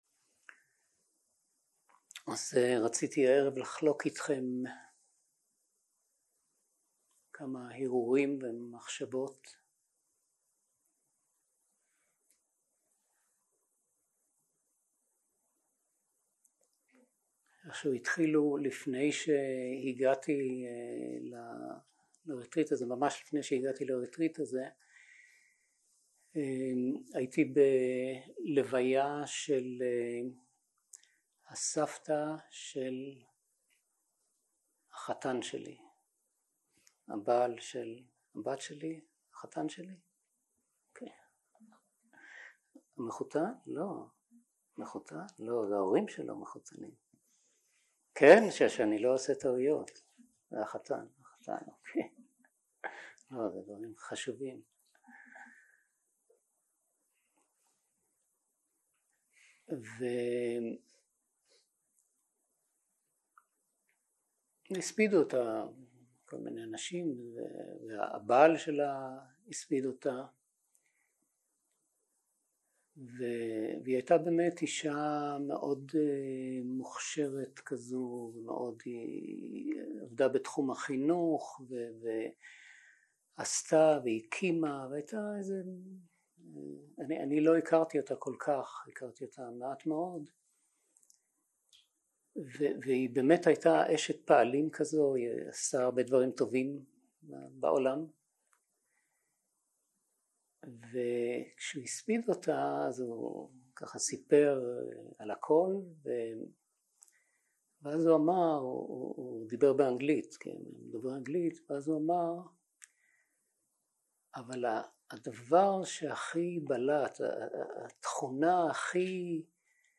יום 3 - ערב - שיחת דהרמה - כוחן המרפא של איכויות הלב - הקלטה 7 0:00 0:00 סוג ההקלטה: Dharma type: Dharma Talks שפת ההקלטה: Dharma talk language: Hebrew